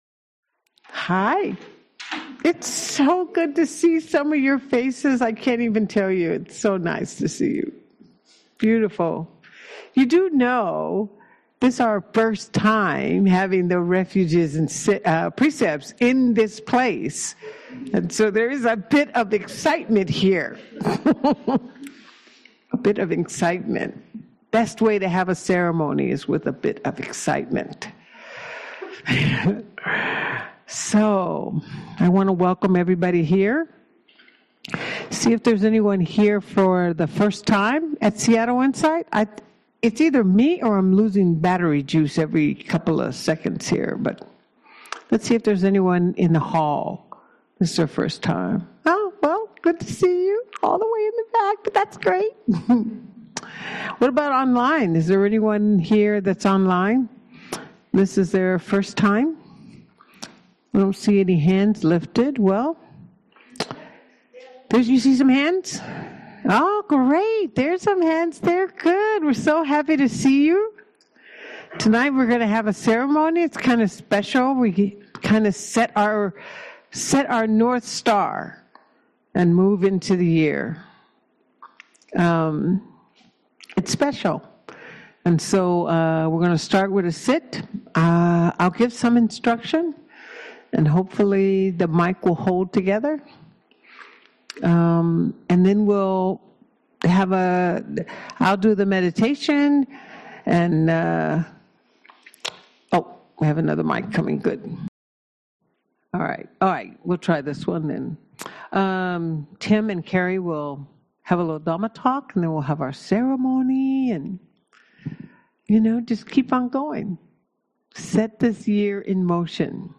Audio recordings of Buddhist teachings and discussions with local and visiting teachers of the Dhamma.